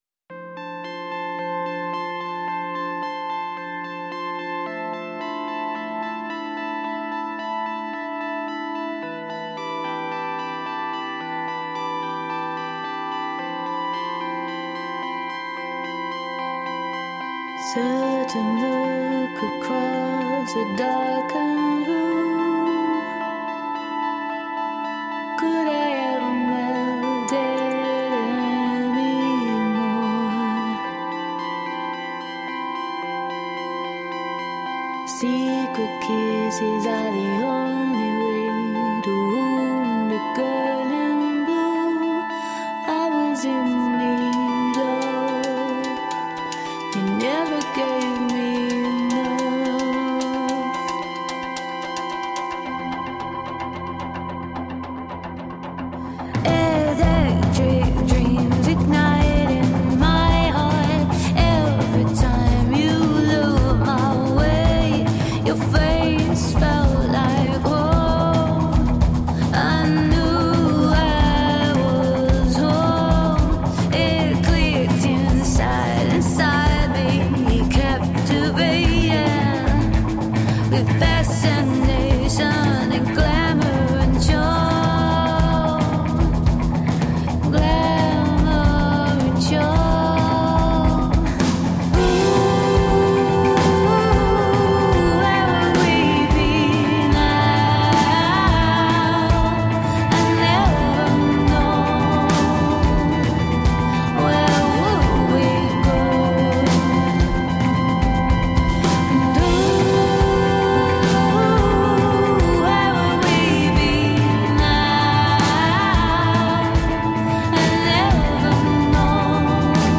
Groovy, french downtempo electro-rock songs.
Tagged as: Electro Rock, Other, Woman Singing Electro Pop